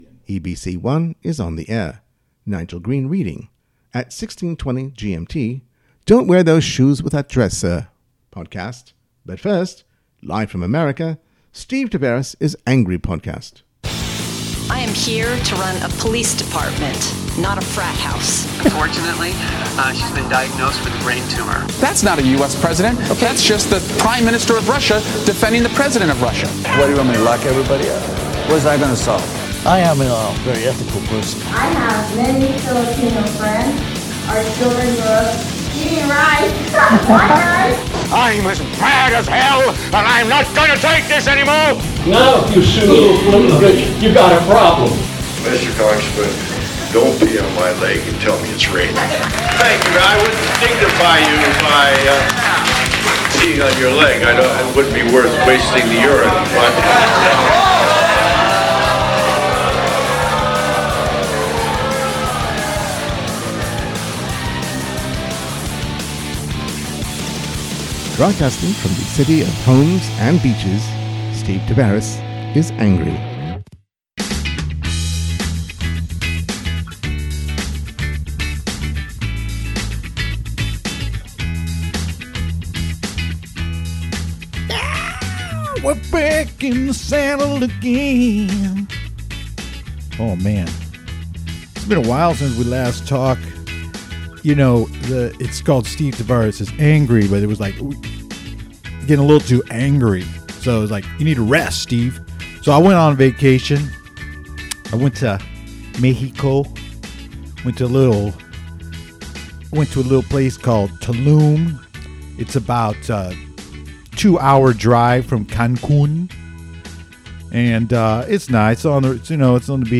Episode 11–Alameda County Democratic Party endorsement preview; interview with Pamela Price
In the hot seat, I welcome Oakland mayoral candidate Pamela Price to the podcast. You will find her take on how the central committee does its business quite fascinating.